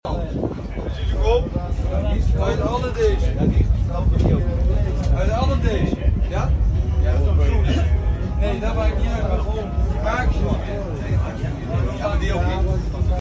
Ducati 125 Two Stroke In Sound Effects Free Download